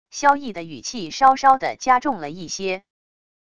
萧易的语气稍稍的加重了一些wav音频生成系统WAV Audio Player